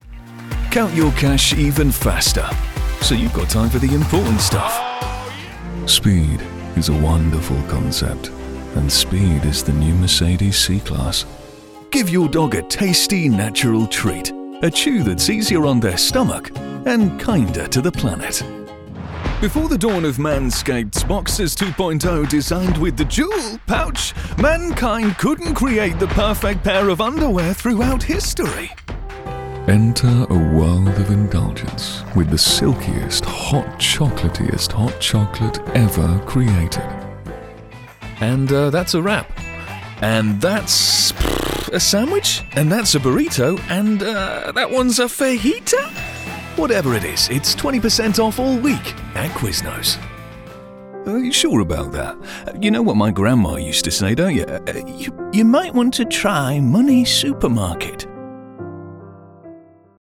Best Male Voice Over Actors In September 2025
Yng Adult (18-29) | Adult (30-50)